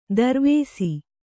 ♪ darvēsi